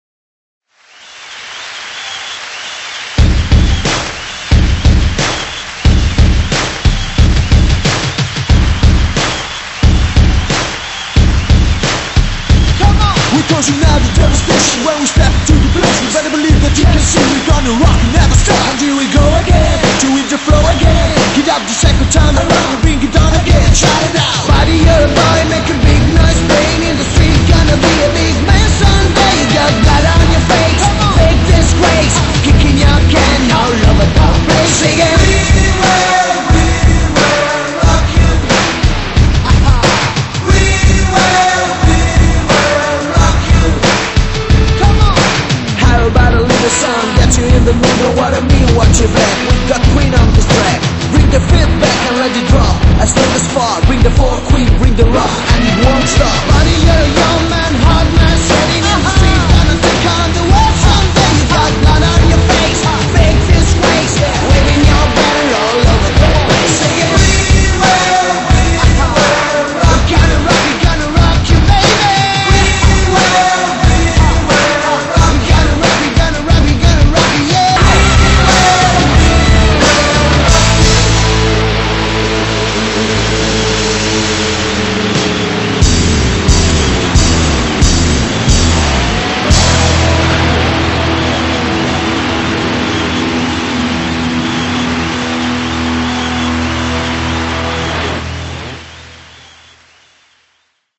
BPM180--1
Audio QualityPerfect (High Quality)